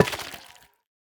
Minecraft Version Minecraft Version snapshot Latest Release | Latest Snapshot snapshot / assets / minecraft / sounds / block / sculk_catalyst / break3.ogg Compare With Compare With Latest Release | Latest Snapshot